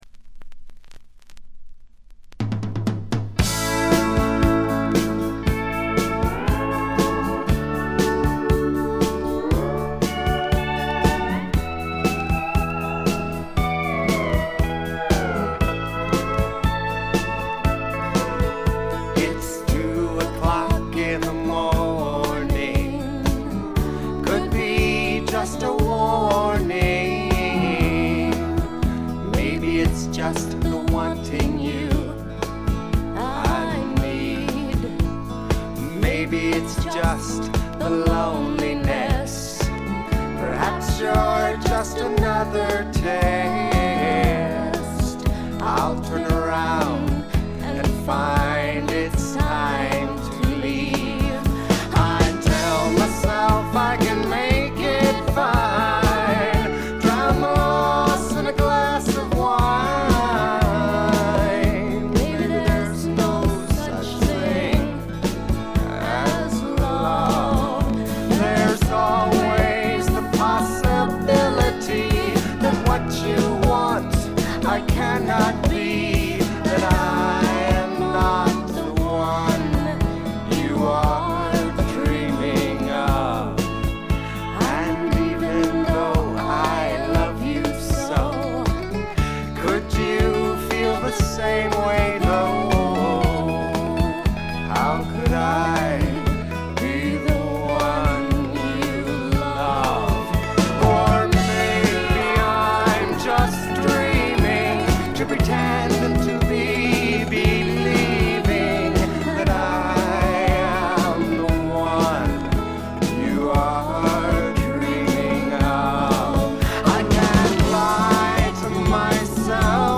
部分試聴ですが、軽微なバックグラウンドノイズにチリプチ少し。
70年代初頭の感覚が強い「あの感じの音」です。
試聴曲は現品からの取り込み音源です。